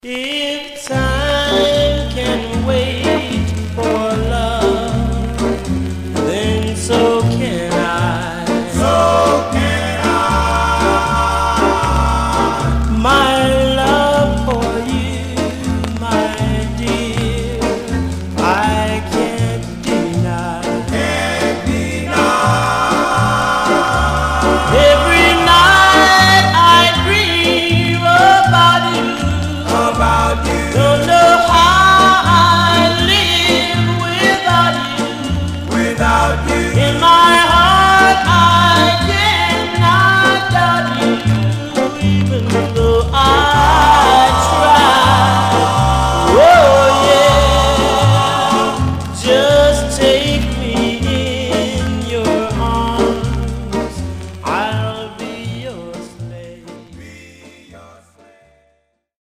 Some surface noise/wear Stereo/mono Mono
Male Black Group